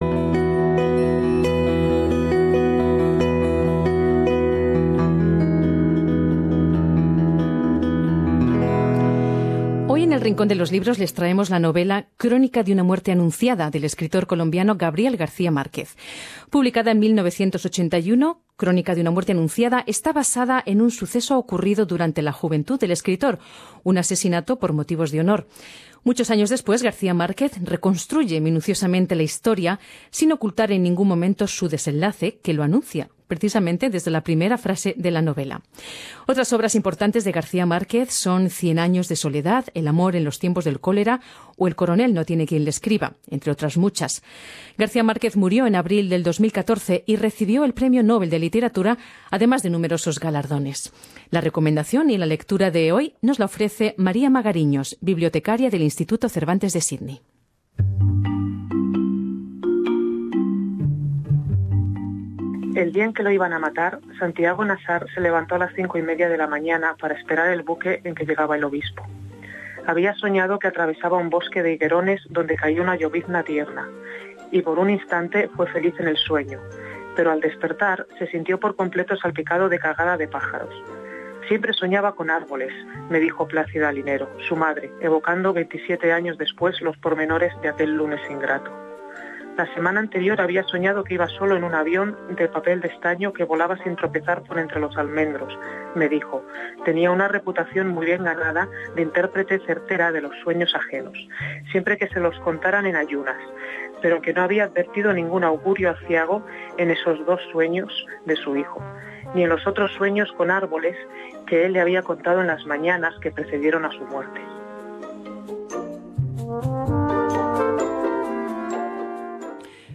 Escucha la recomendación y la lectura